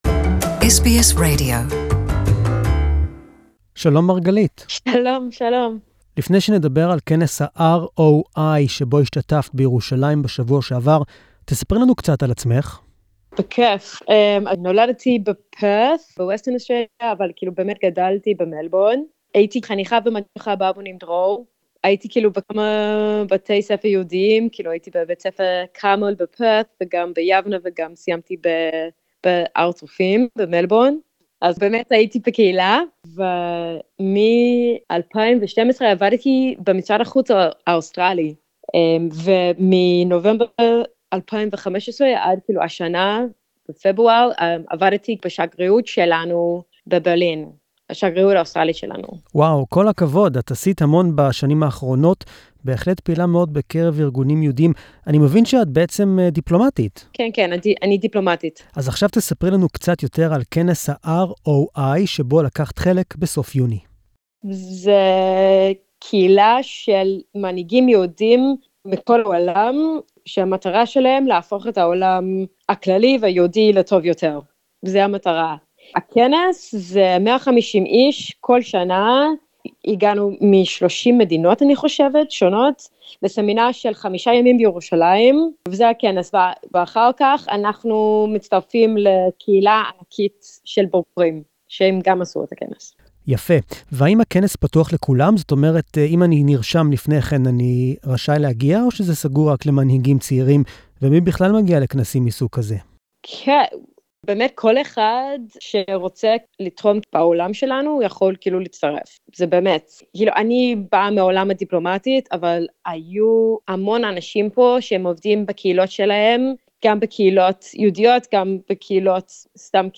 A Hebrew Interview